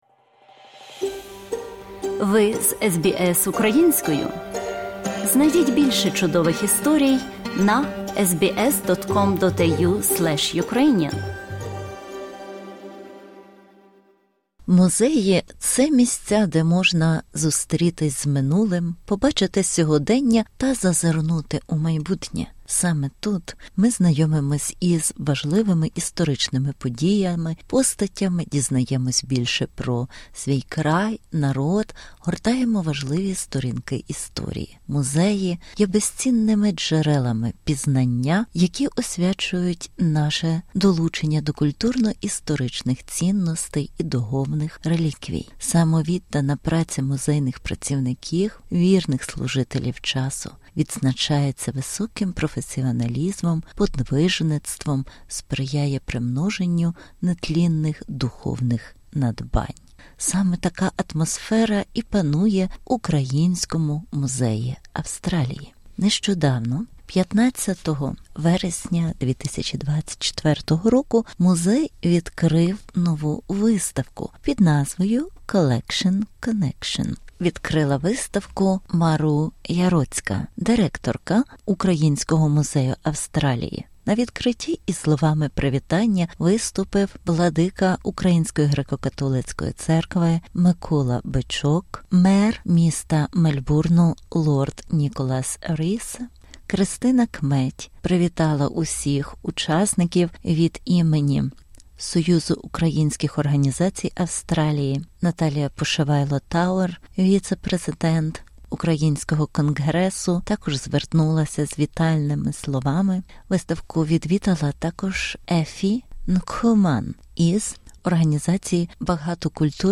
Репортаж з урочистого відкриття нової виставки Collection/Connection в Українському музеї Австралії